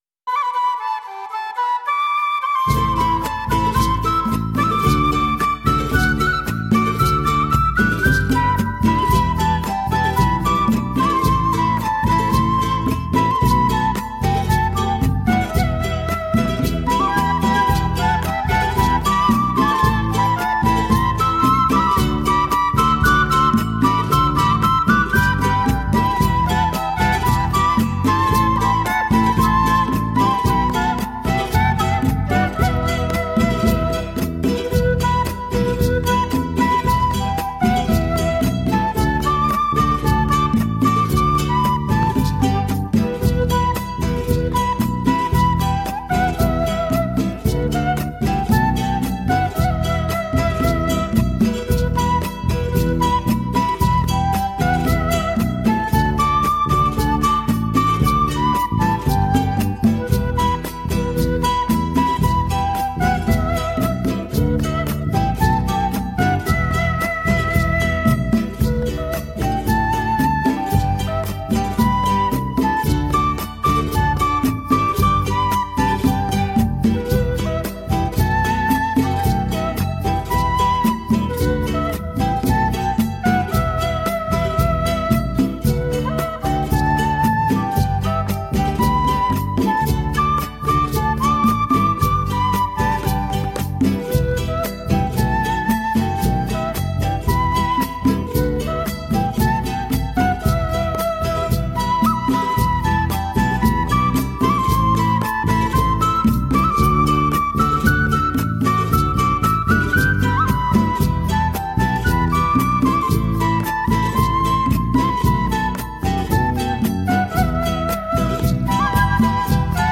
who plays indigenous music of Ecuador and the Andes regions